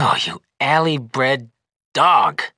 vs_fMortexx_cuss.wav